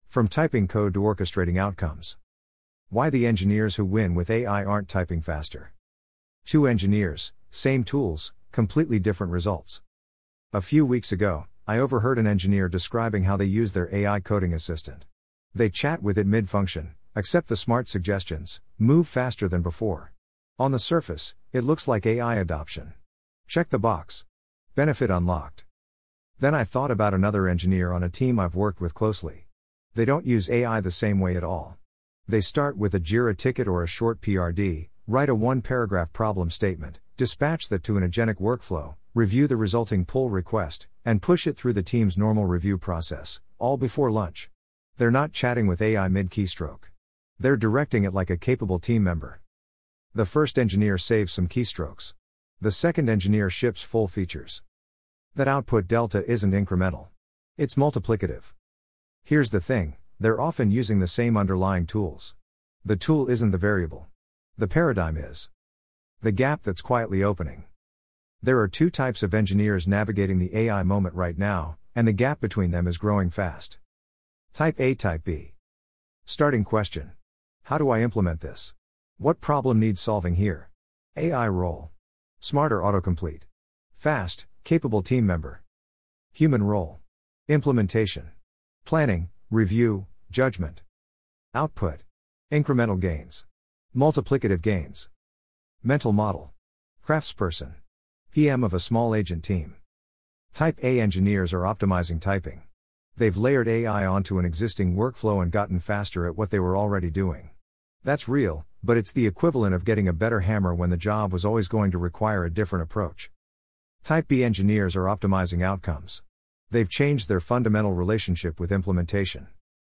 Listen 9m 52s Narrated by Matthew (AI)